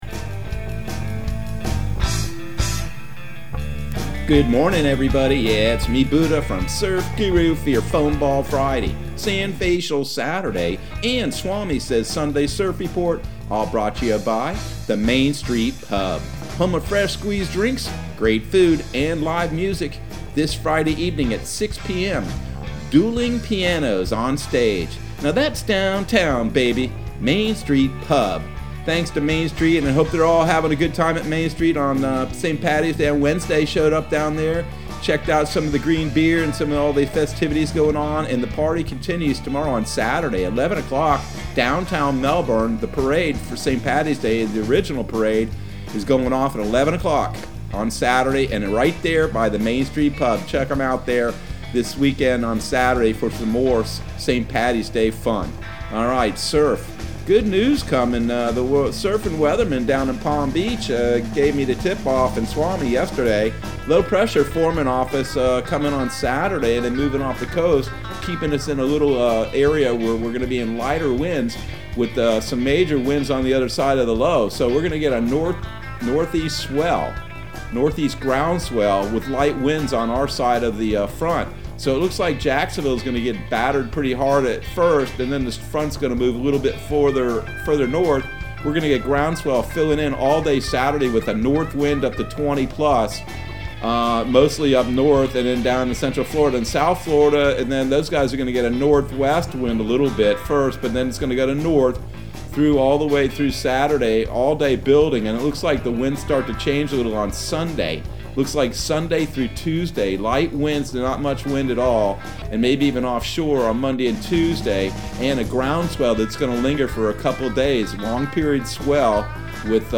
Surf Guru Surf Report and Forecast 03/19/2021 Audio surf report and surf forecast on March 19 for Central Florida and the Southeast.